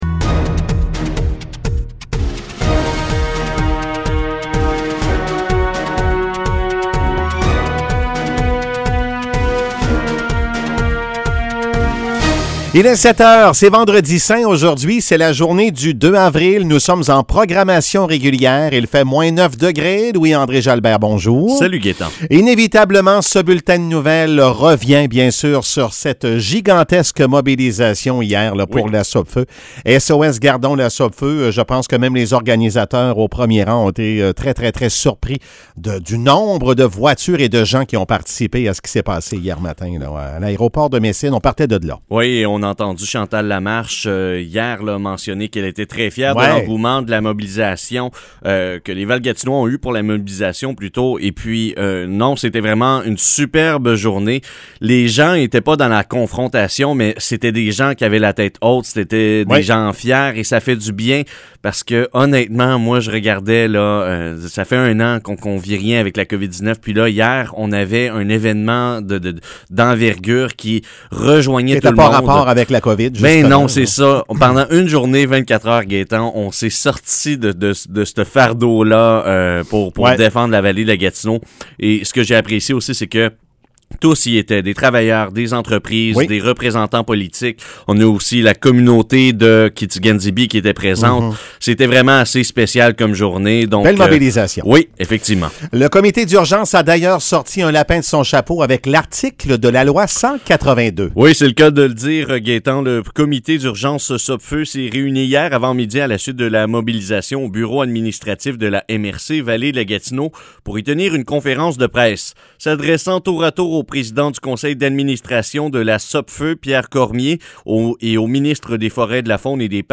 Nouvelles locales - 2 Avril 2021 - 7 h